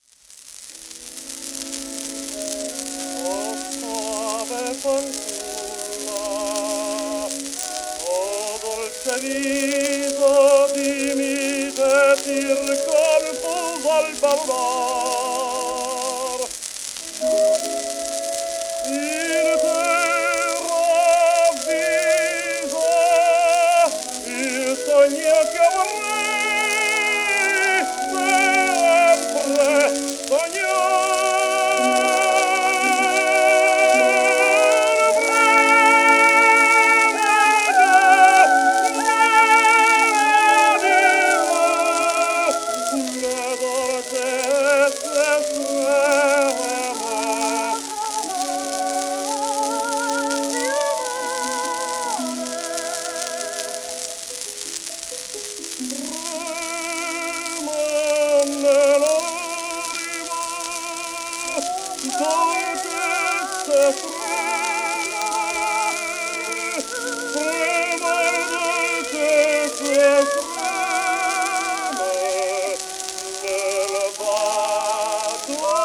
エンリコ・カルーソーTen:1873-1921)&ネリー・メルバ(Sop:1861-1931)
w/オーケストラ
1907年録音
旧 旧吹込みの略、電気録音以前の機械式録音盤（ラッパ吹込み）
caruso_melba_puccini_laboheme.m4a